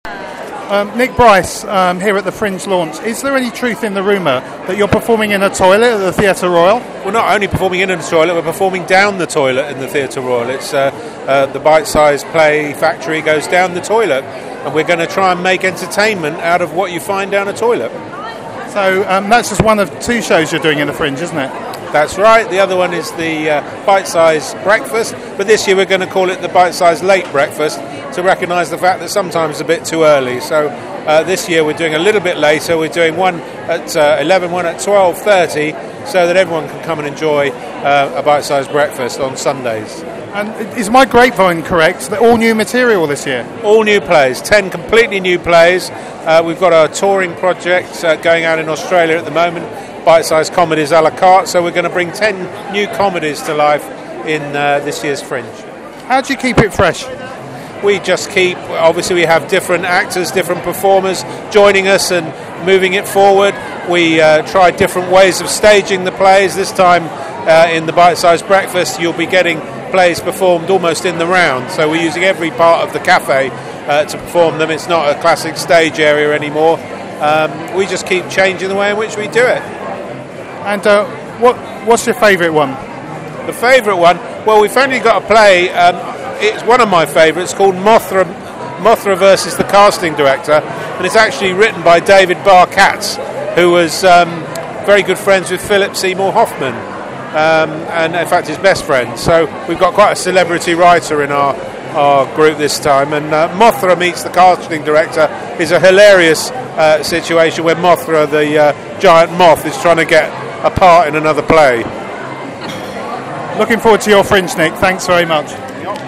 Bite-Size is back with two brand new shows, one for adults, one for kids. We caught up with him briefly at the Brighton Fringe Launch.